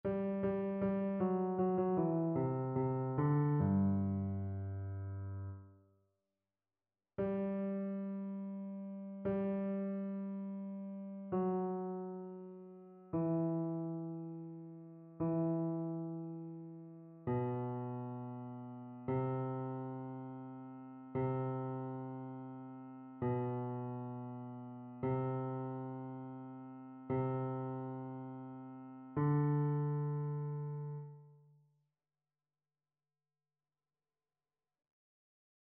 Basse
annee-b-temps-ordinaire-23e-dimanche-psaume-145-basse.mp3